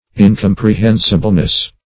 -- In*com`pre*hen"si*ble*ness, n. --
incomprehensibleness.mp3